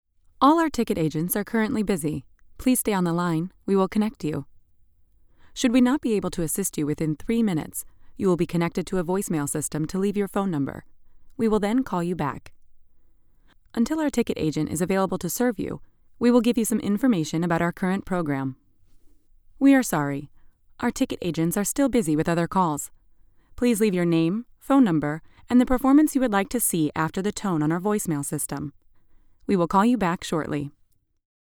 Englisch (Amerikanisch)
Junge, Cool, Vielseitig, Zuverlässig, Warm
Telefonie